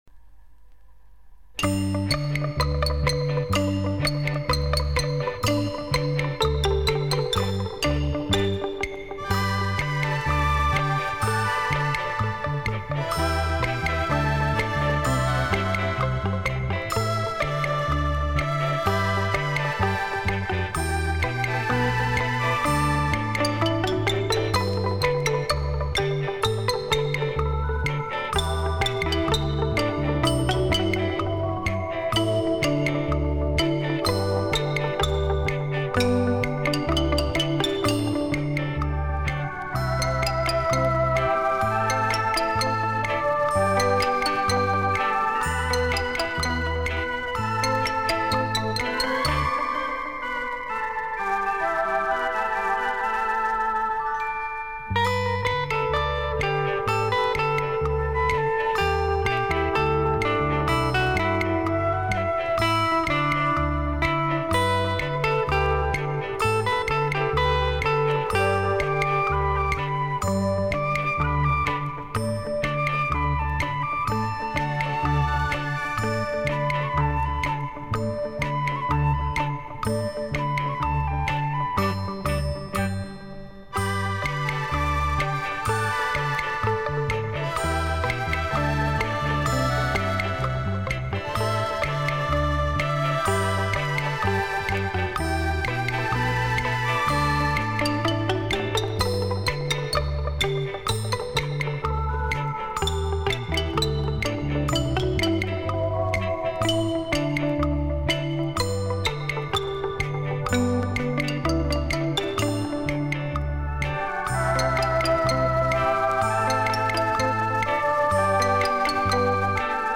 GUITAR MUSIC